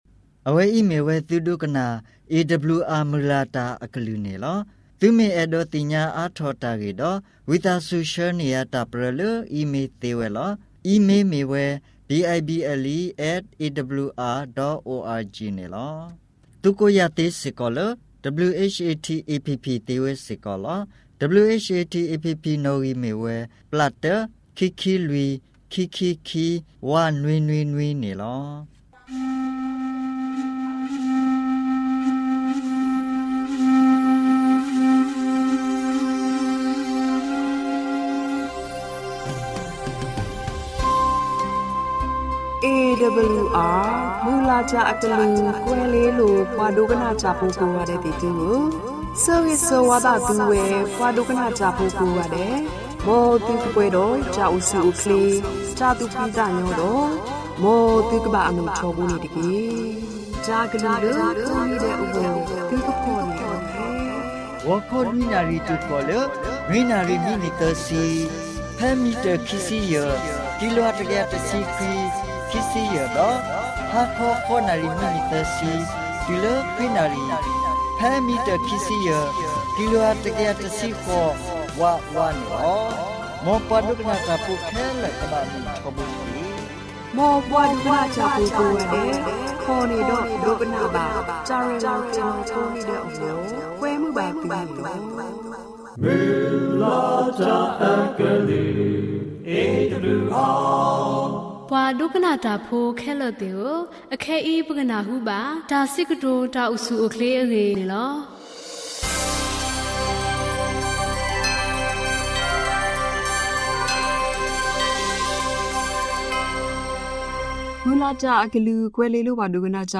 Karen radio program by Adventist World Radio